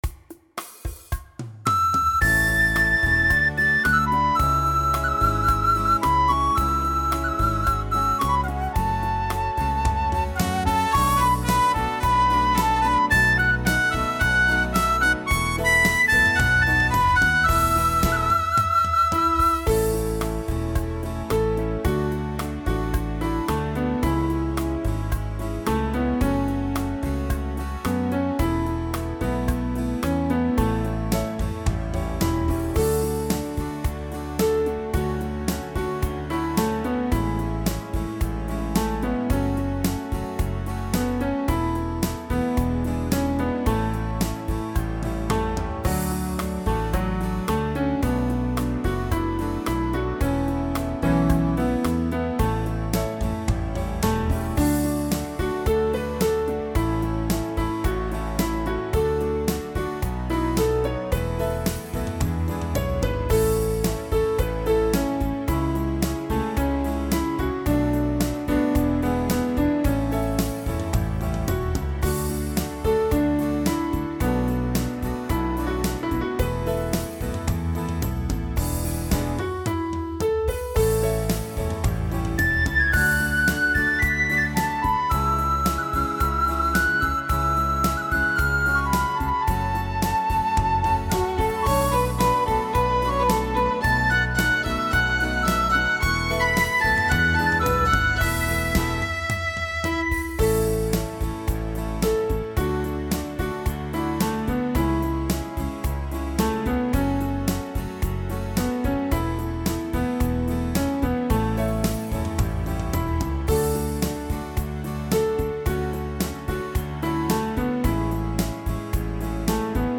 •   Beat  02.